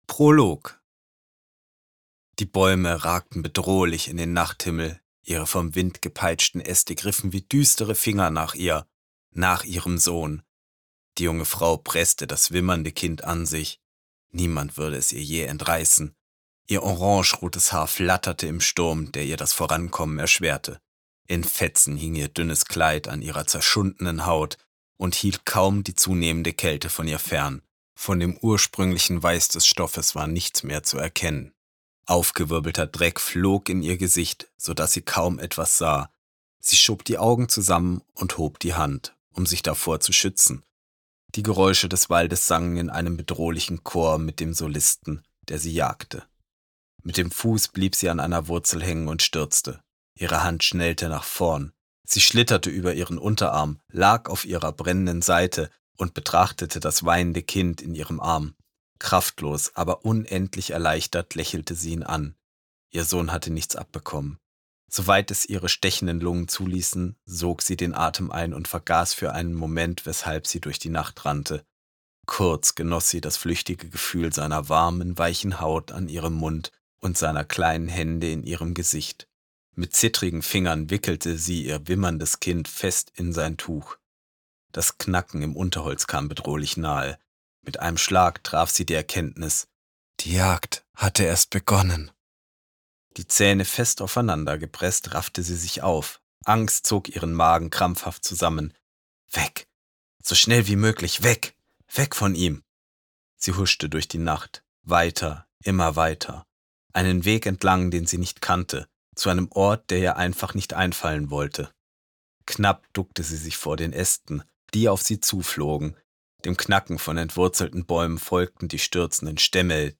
Hörbuch | Fantasy